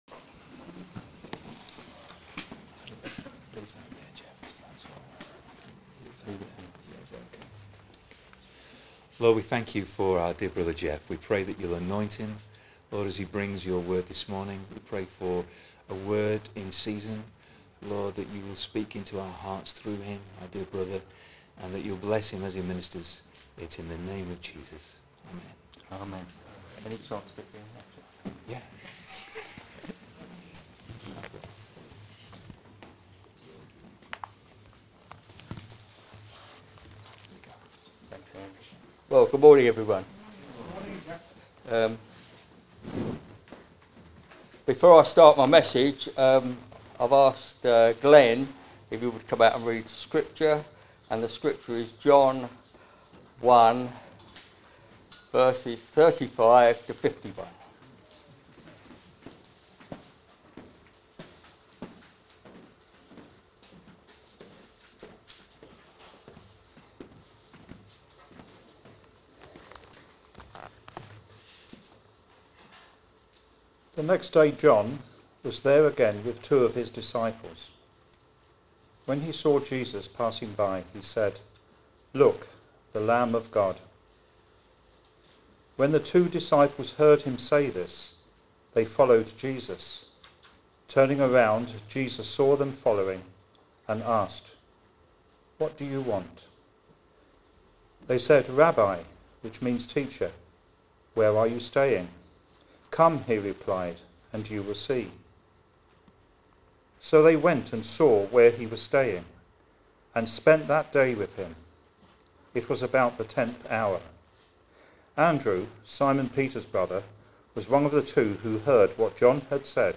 Good evening.